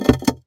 gear-shift-sfx.mp3